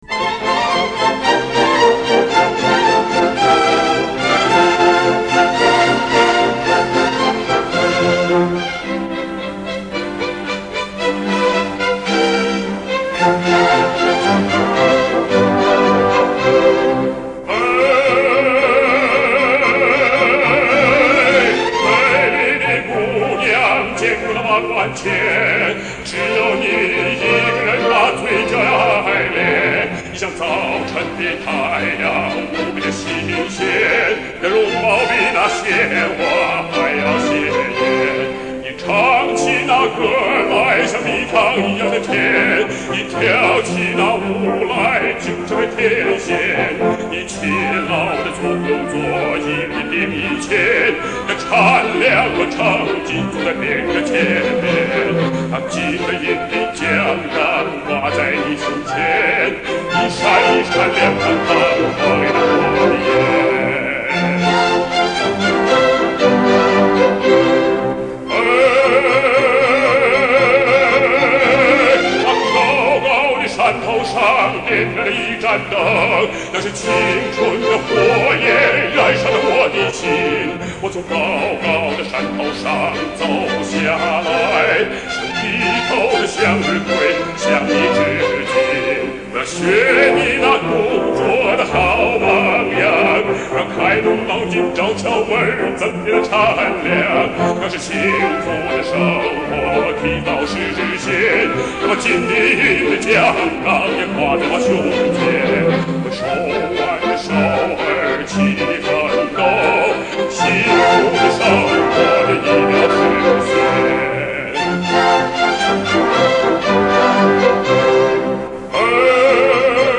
1988年在汉城演出实况